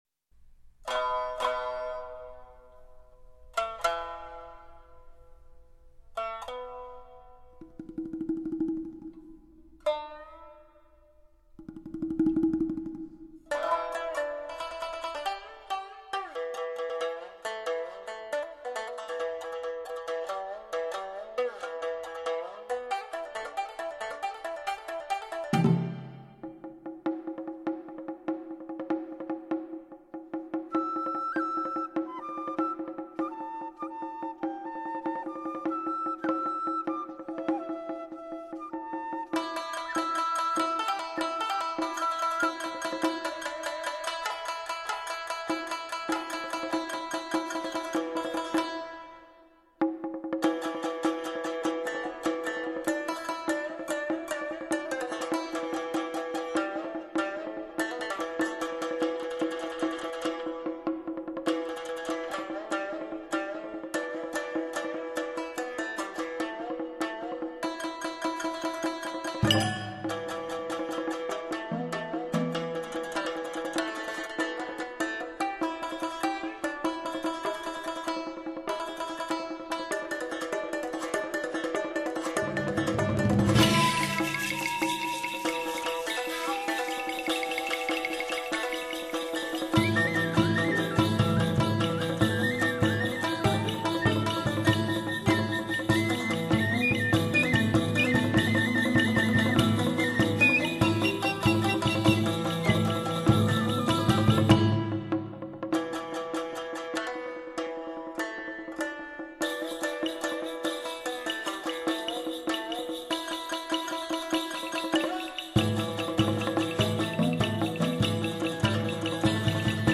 В том числе и благодаря красивой инструментальной музыке.